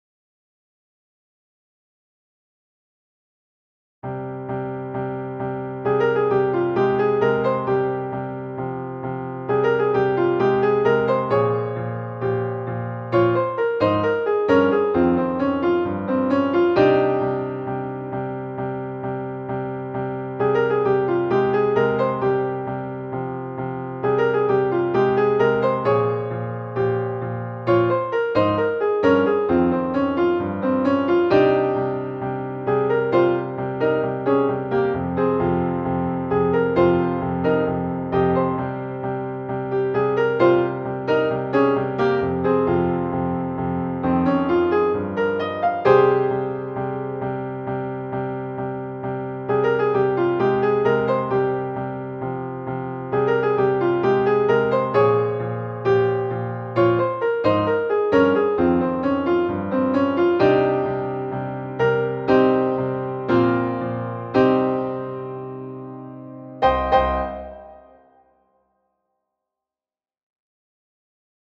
Piano Solo
More jazz titles
Phrygian Mode